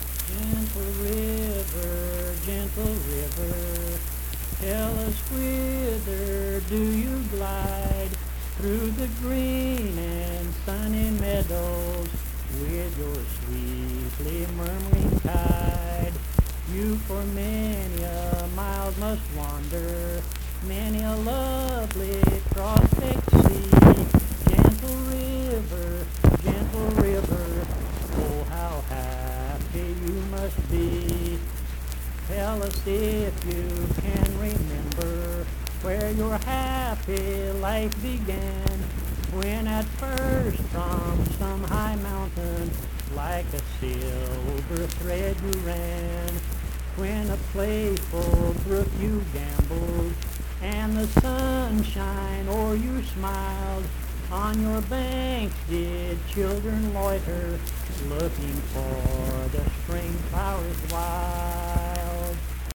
Gentle River - West Virginia Folk Music | WVU Libraries
Accompanied (guitar) and unaccompanied vocal music
Verse-refrain 4(4). Performed in Mount Harmony, Marion County, WV.
Voice (spoken)